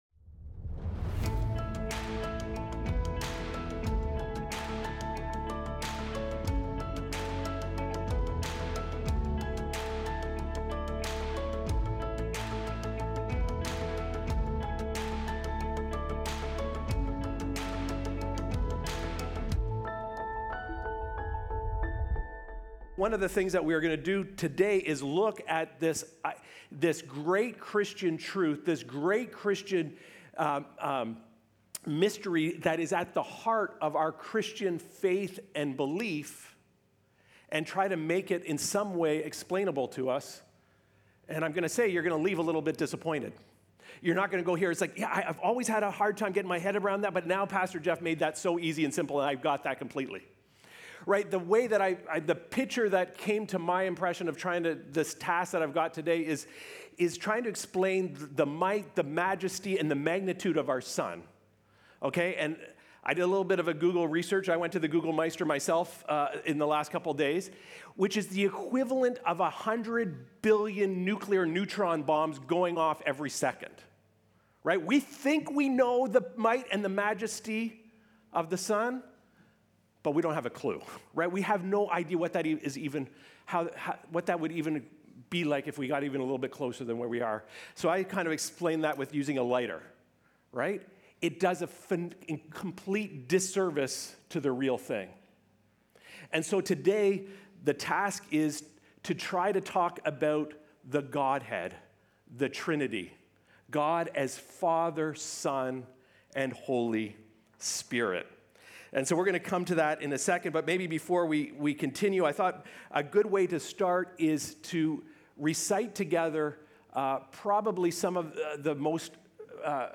Recorded Sunday, October 5, 2025, at Trentside Fenelon Falls.